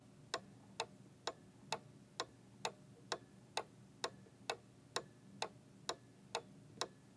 Cuckoo Clock Ticking
10 bell bells cathedral chime chimes church church-bell sound effect free sound royalty free Animals